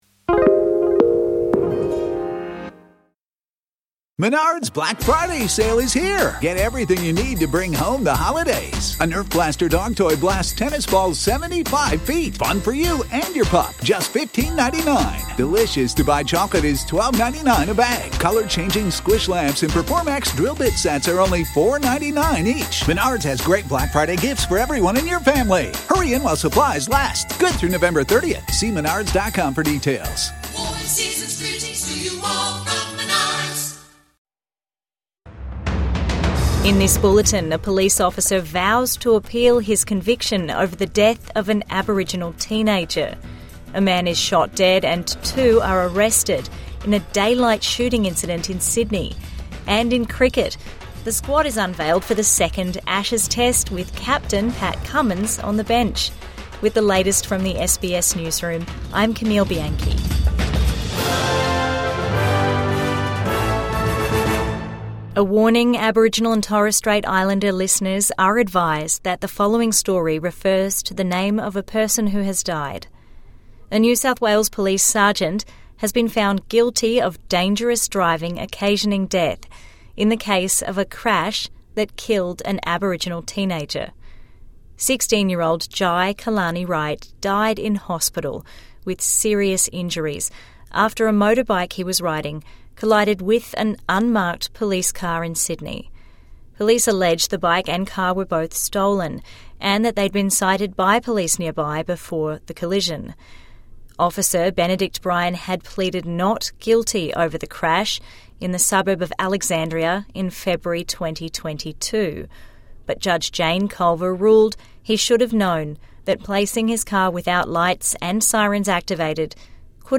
Police officer convicted over teen's death will appeal | Evening News Bulletin 28 November 2025